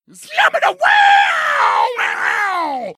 mouth-guitar_04